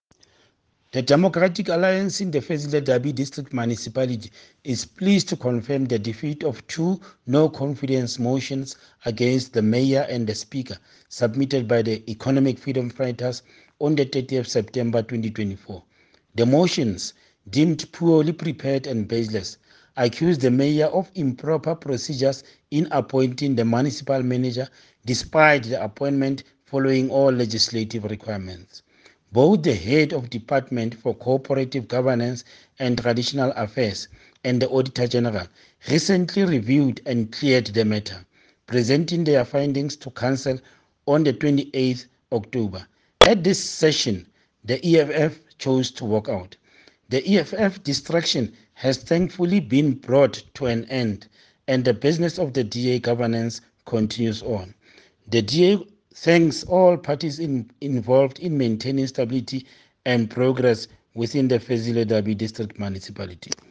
Issued by Stone Makhema – DA Chief Whip Fezile Dabi District Municipality
English and Sesotho soundbites by Cllr Stone Makhema